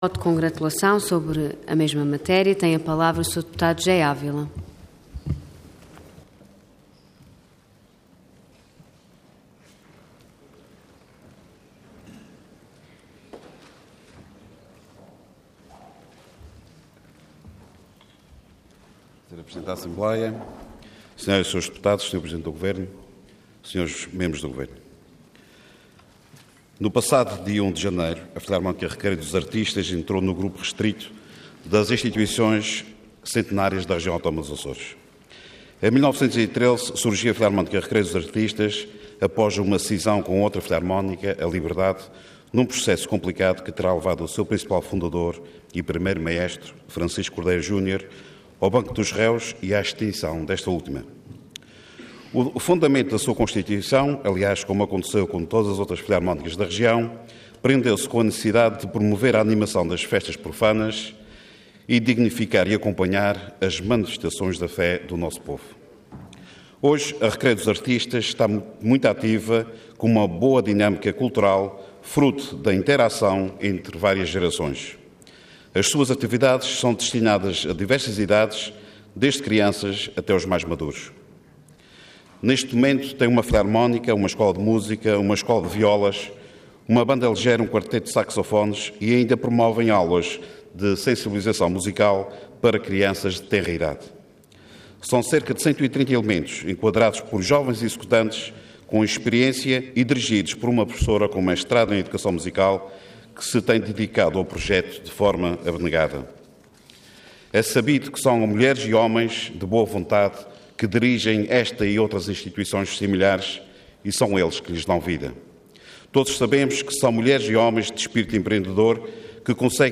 Detalhe de vídeo 17 de janeiro de 2013 Download áudio Download vídeo Diário da Sessão Processo X Legislatura 100.º Aniversário da Filarmónica Recreio dos Artistas. Intervenção Voto de Congratulação Orador José Ávila Cargo Deputado Entidade PS